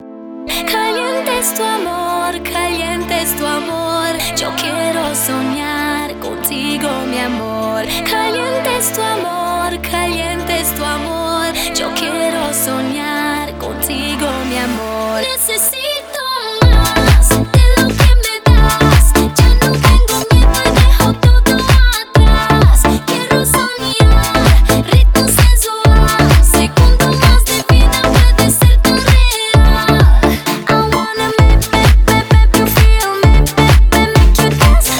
2012-05-04 Жанр: Танцевальные Длительность